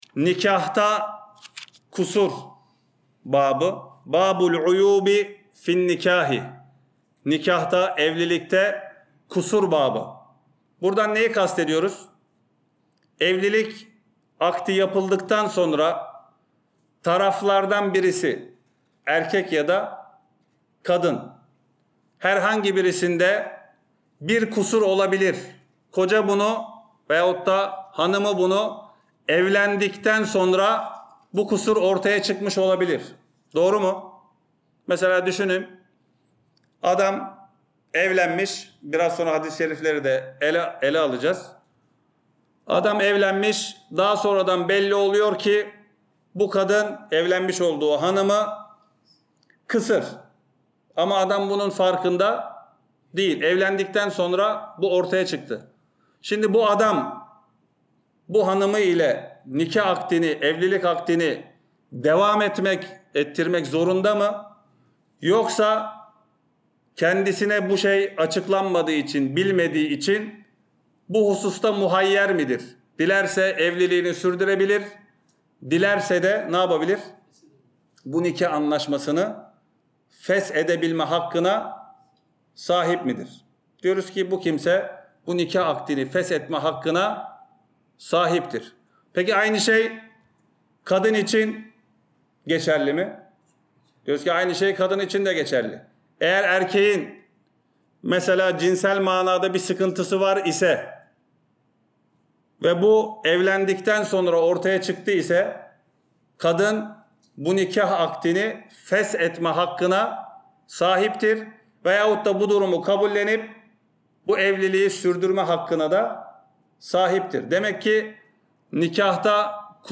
Ders - 3- NİKAHTAKİ KUSUR BÖLÜM